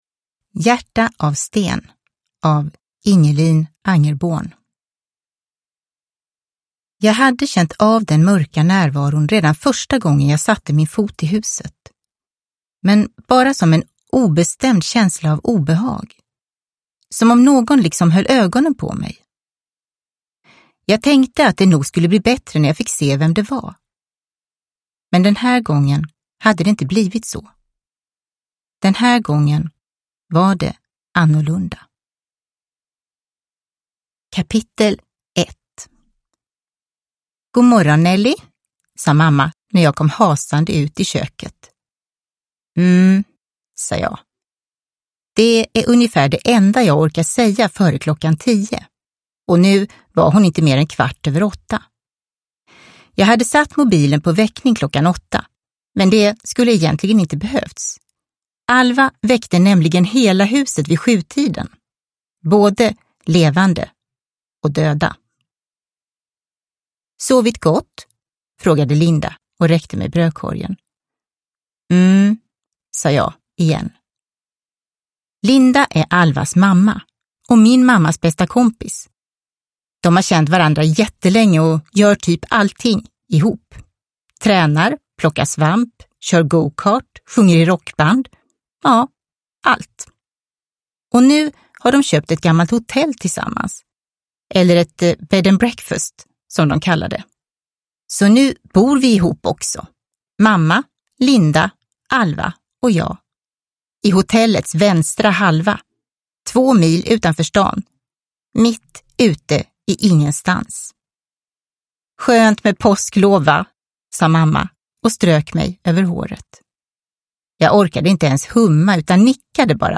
Hjärta av sten – Ljudbok – Laddas ner